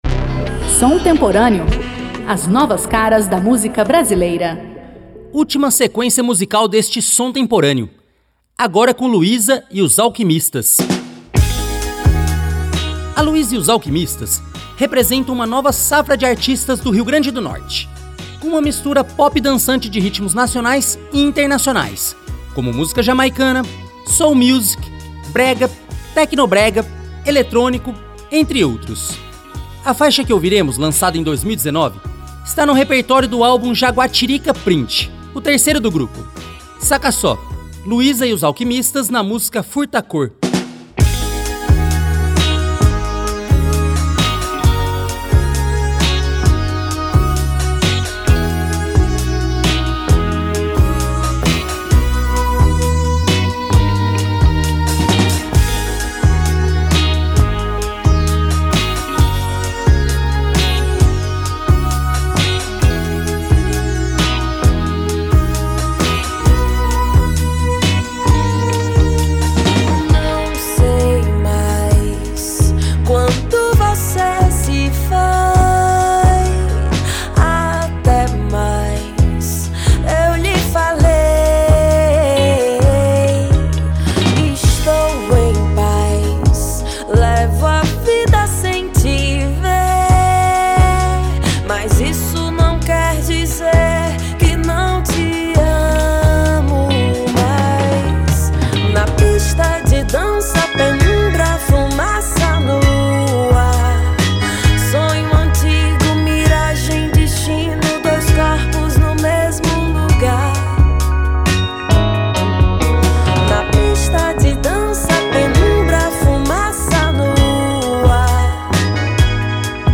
novíssima música brasileira